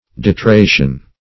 deterration - definition of deterration - synonyms, pronunciation, spelling from Free Dictionary
Search Result for " deterration" : The Collaborative International Dictionary of English v.0.48: Deterration \De`ter*ra"tion\, n. [L. de + terra earth: cf. F. d['e]terrer to unearth.] The uncovering of anything buried or covered with earth; a taking out of the earth or ground.